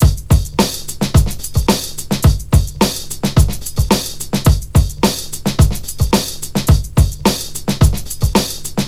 • 108 Bpm Breakbeat F# Key.wav
Free drum loop sample - kick tuned to the F# note. Loudest frequency: 1832Hz
108-bpm-breakbeat-f-sharp-key-Bxs.wav